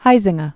(hīzĭng-ə, houzĭng-ä), Johan 1872-1945.